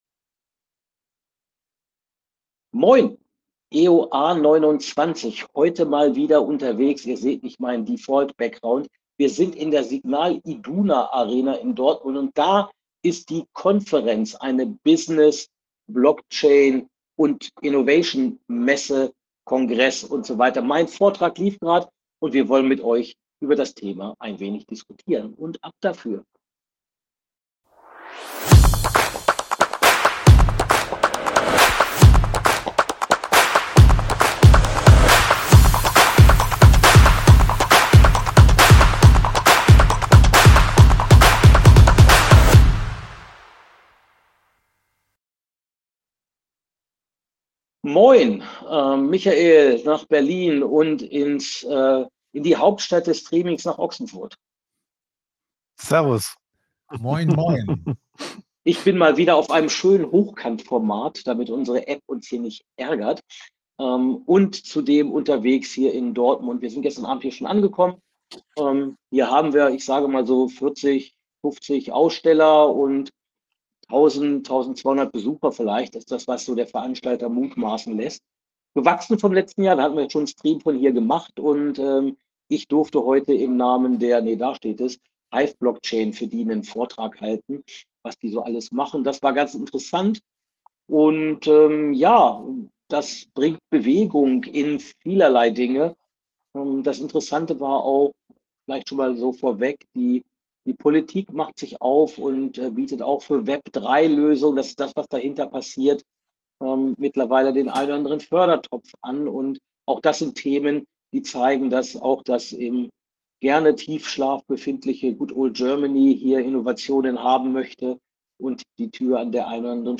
Im Signal Iduna Stadium geht es heute mal nicht um Fußball. EOA29 sendet live von der Conf3rence, wo Business und Blockchain neue Innovationen treiben.